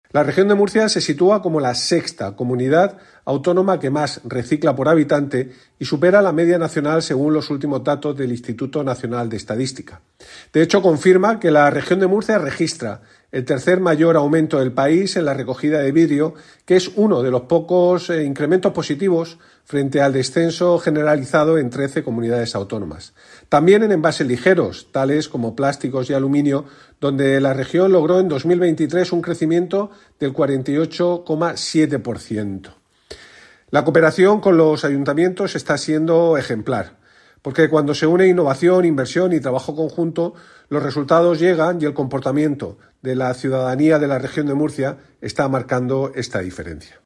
Declaraciones del consejero de Medio Ambiente, Universidades, Investigación y Mar Menor, Juan María Vázquez, sobre el compromiso de la Región de Murcia con la responsabilidad ambiental.